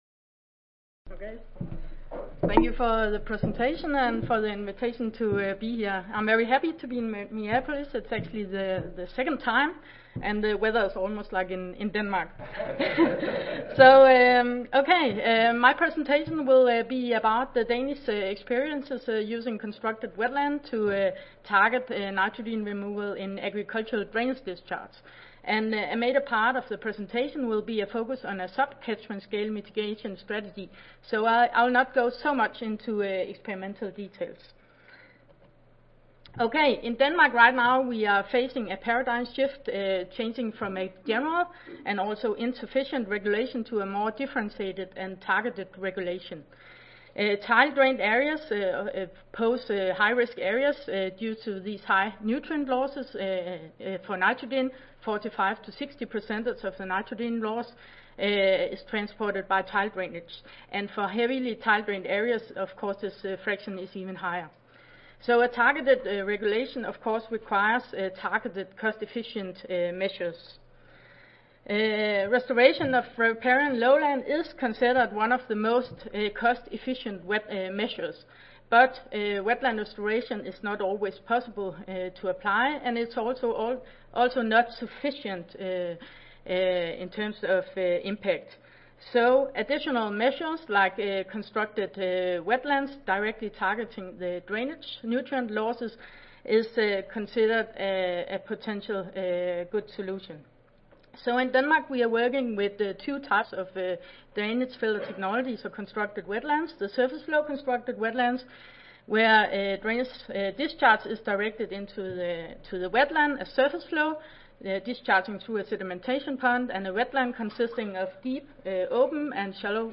Aarhus University Audio File Recorded Presentation